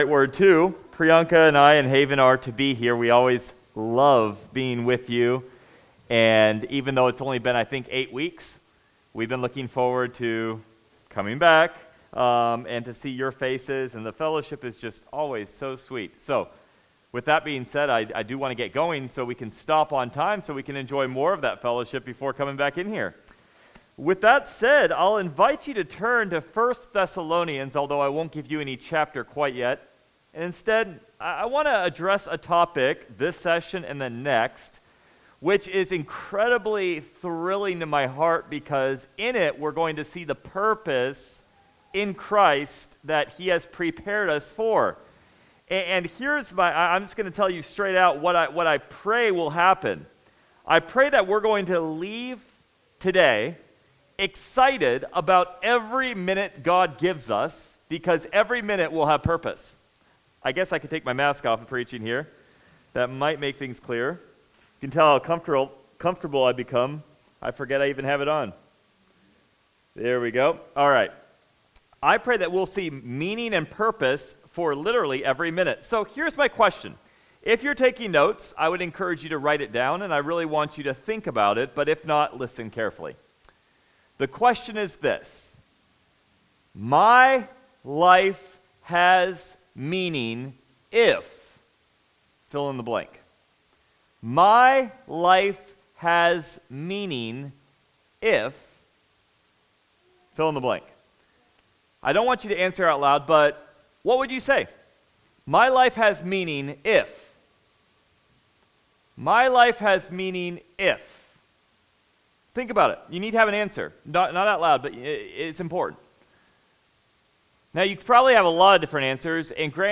1 Thess 3:8 Service Type: Sunday Topics: Discipleship « Creation Faith & Science Dicipleship